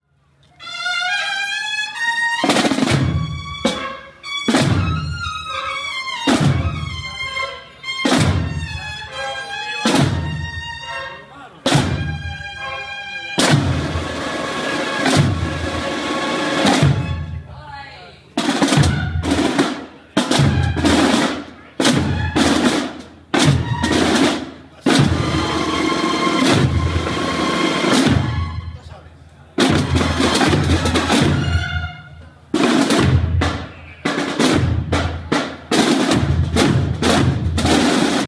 Tambores
Cofradía Jesús del Huerto y San Diego